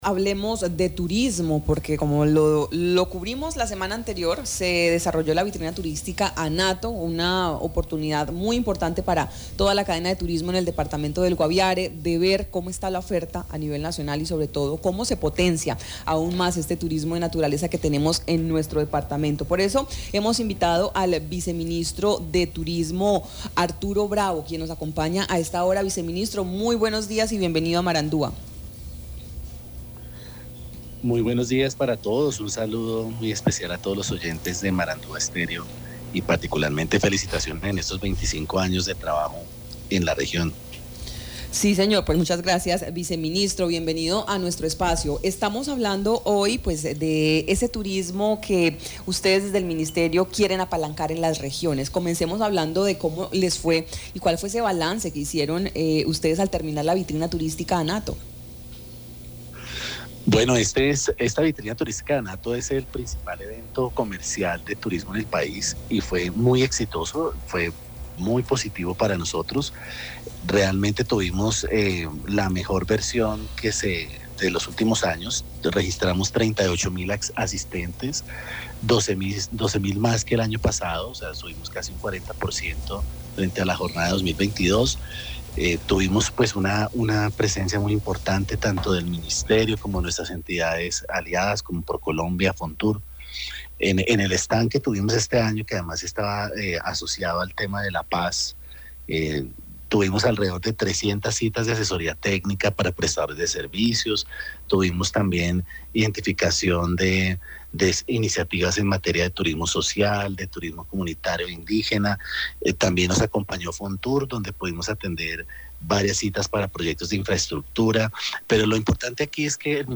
Arturo Bravo, viceministro de Turismo, presentó en Marandua Noticias un balance de la 42 Vitrina Anato, manifestó que hubo 38 mil visitas, con un aumento del 48% más que el año pasado.